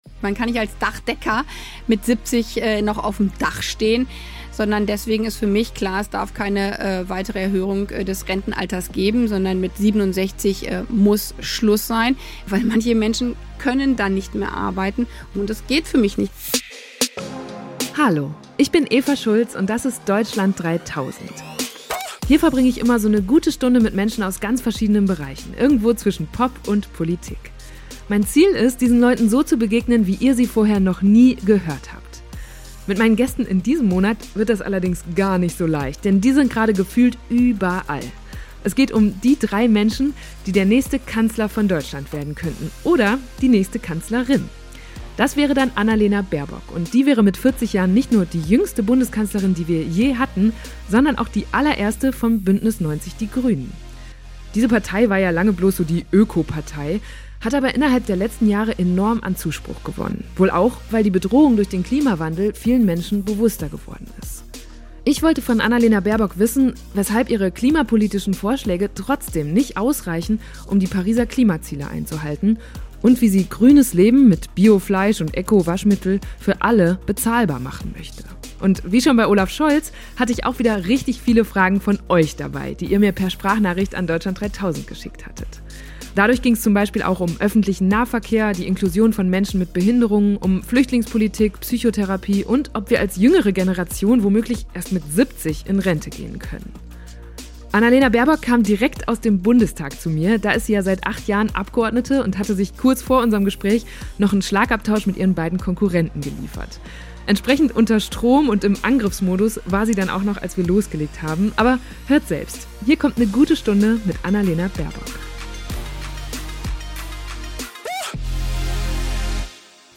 Ich wollte von Annalena Baerbock wissen, weshalb ihre klimapolitischen Vorschläge trotzdem nicht ausreichen, um die Pariser Klimaziele einzuhalten, und wie sie grünes Leben mit Bio-Fleisch und Eco-Waschmittel für alle bezahlbar machen möchte. Wie schon bei Olaf Scholz hatte ich auch wieder richtig viele Fragen von euch dabei, die ihr per Sprachnachricht an Deutschland3000 geschickt hattet. Dadurch ging es zum Beispiel auch um Öffentlichen Nahverkehr, die Inklusion von Menschen mit Behinderungen, um Flüchtlingspolitik, Psychotherapie und ob wir als jüngere Generation womöglich erst mit 70 in Rente gehen können.
Entsprechend unter Strom und im Angriffsmodus war sie dann auch noch, als wir losgelegt haben.